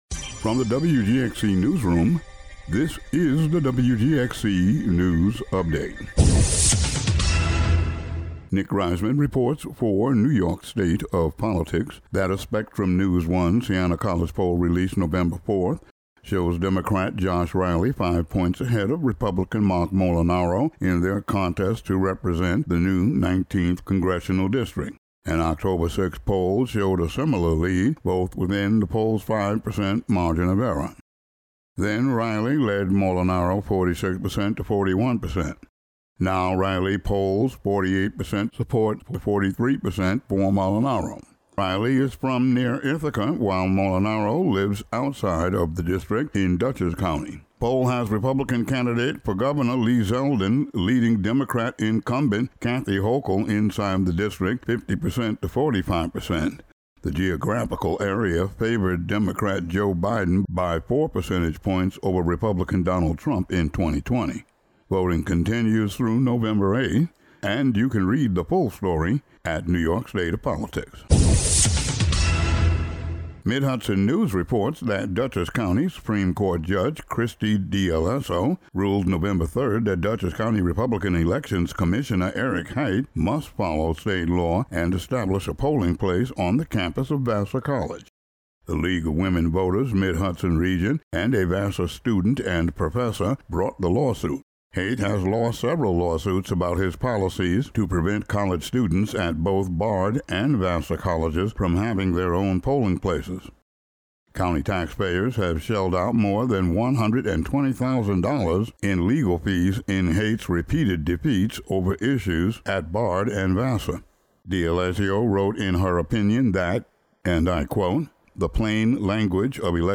Local news update.